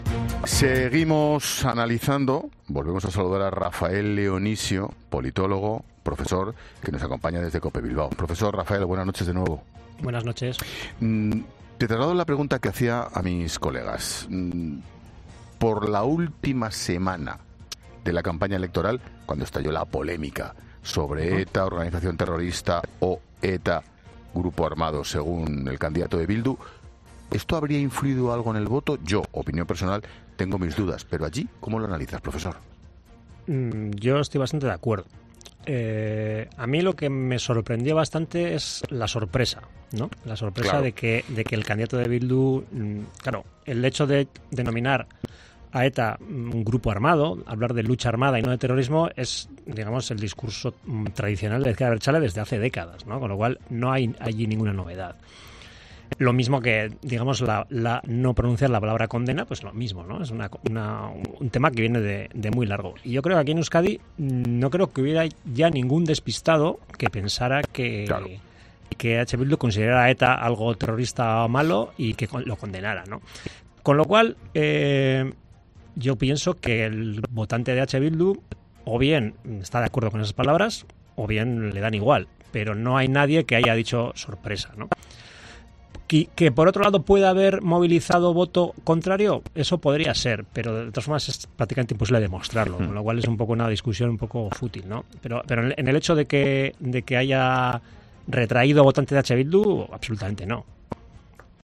Escucha el análisis del politólogo, profesor e investigador